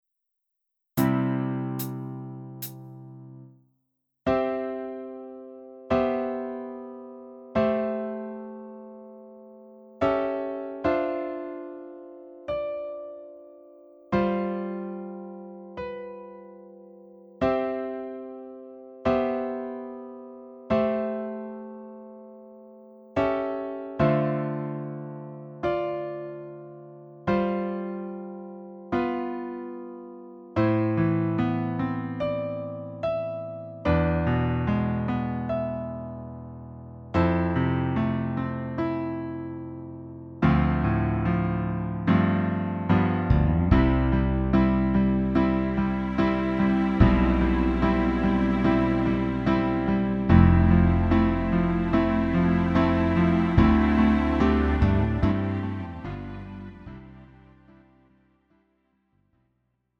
음정 원키 4:28
장르 가요 구분 Lite MR